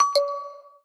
Nada dering pesan Xiaomi
Kategori: Nada dering
nada-dering-pesan-xiaomi-id-www_tiengdong_com.mp3